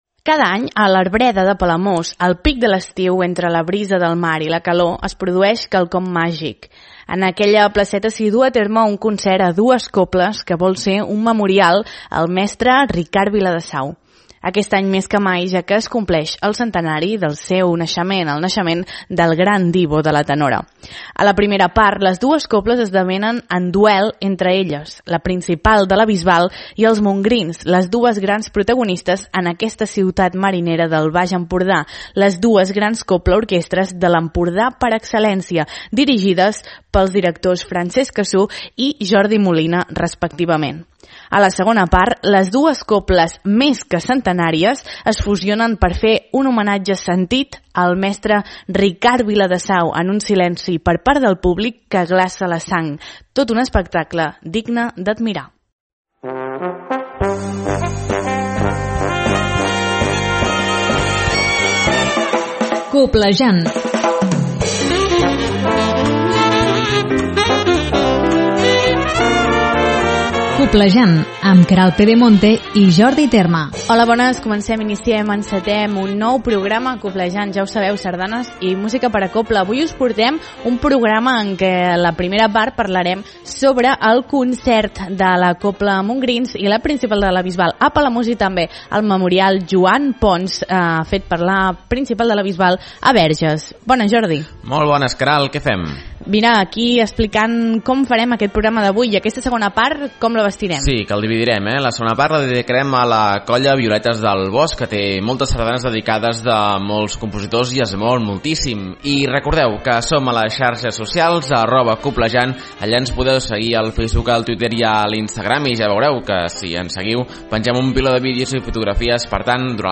En la segona part del programa escoltarem sardanes dedicades a la Colla sardanista Violetes del Bosc de Barcelona ja que en té moltes de dedicades de compositors com Francesc Cassú, Manel Saderra, Jesús Ventura, Pepita Llunell o Agsutí Borgunyó.